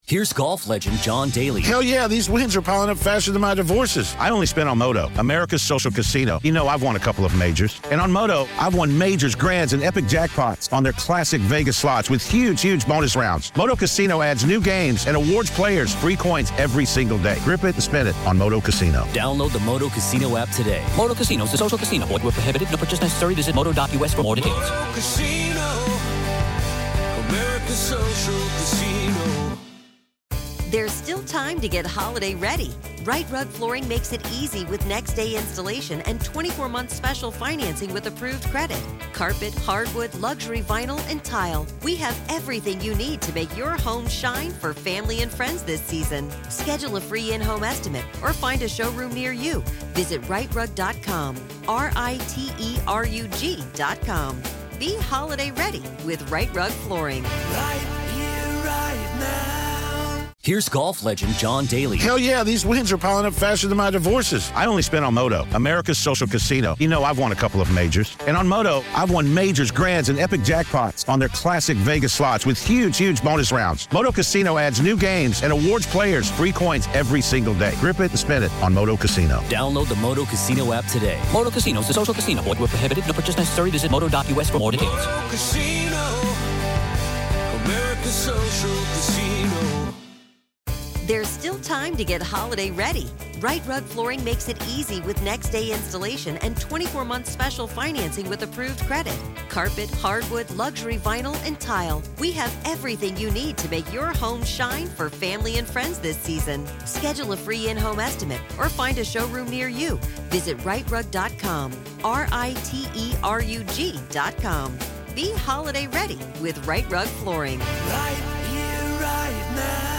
You’ll hear unfiltered courtroom audio, direct from the trial